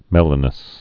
(mĕlə-nəs)